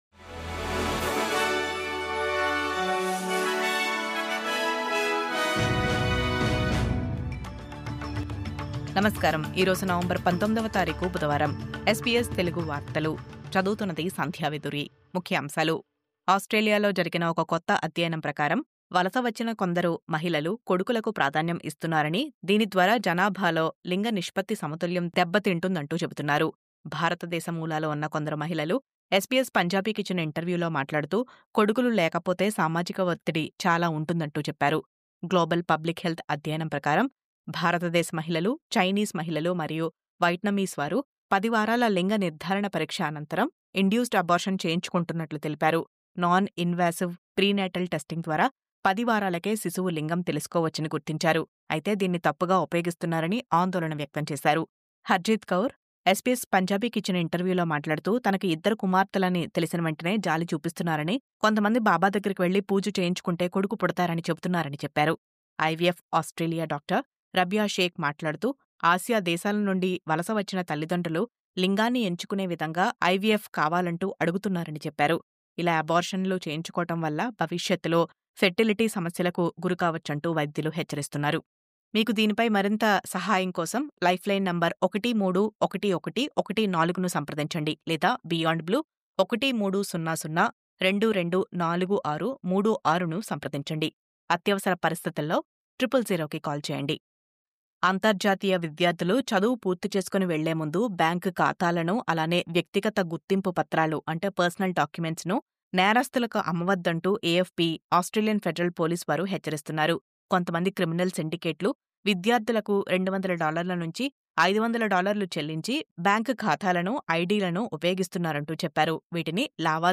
News update: మగ సంతానం కోసం అబార్షన్లు...10 వారాల లింగ నిర్ధారణ తర్వాత induced అబార్షన్లపై ఆందోళనలు..